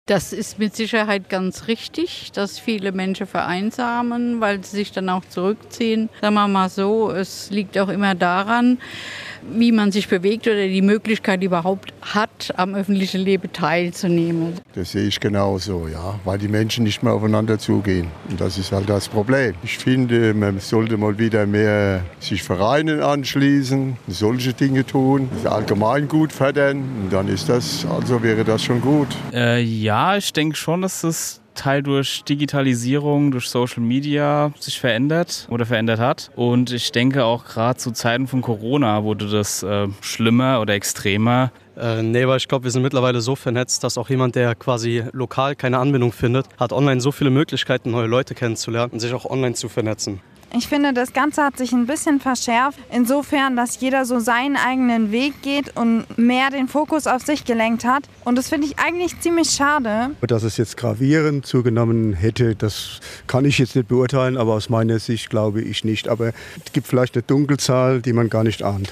Umfrage - Wächst die Einsamkeit in unserer Gesellschaft wirklich?
Bürger aus Kaiserslautern antworten.